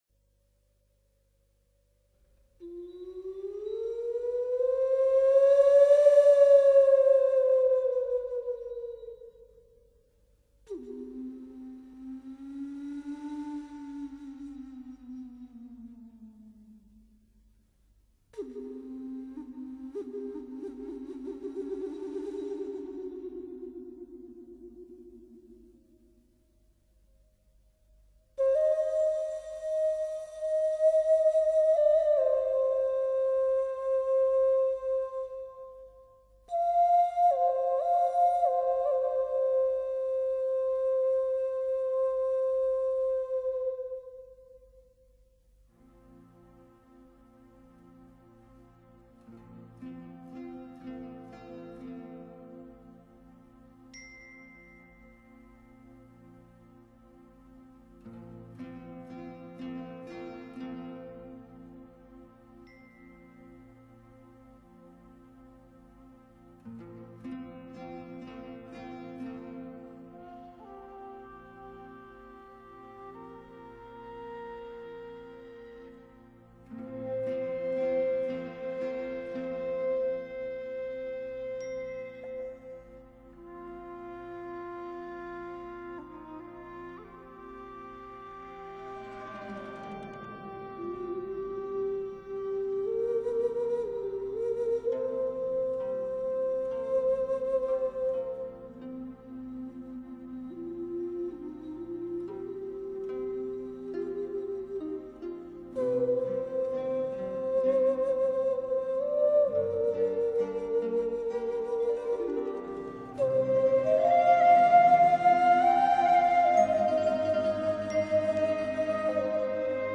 在我国古代的吹奏乐器中，埙是最古老的一种，
质：古朴、浑厚、低沉、沧桑、神秘、哀婉，所以埙特别擅长抒发哀
怨之情和制造肃穆、旷古、凄厉的特殊效果，一直以来被视为一种特